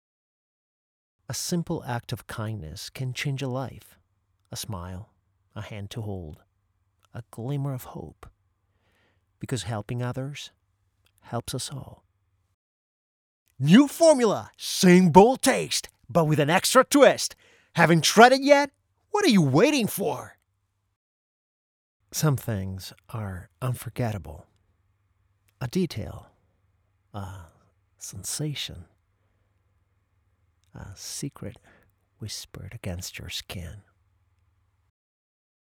Demo Voce Inglese 2025
English Voice Demo 2025